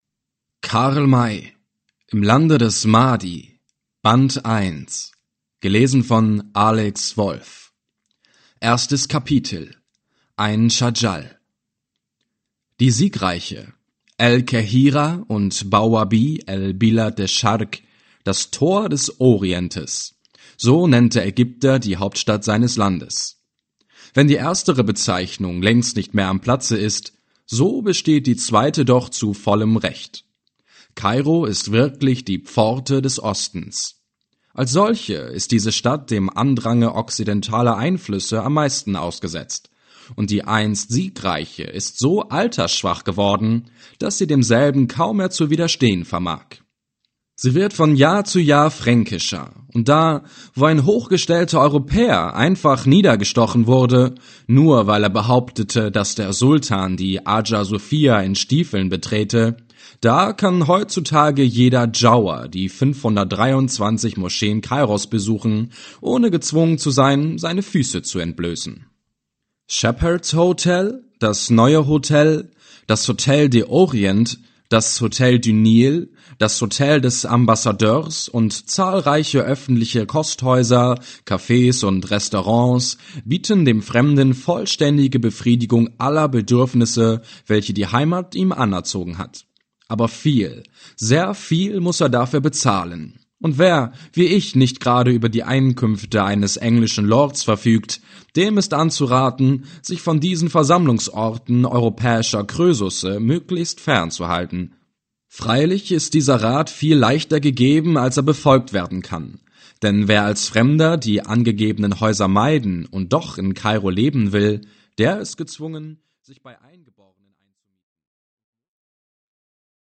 Audiobook - Im Lande des Mahdi I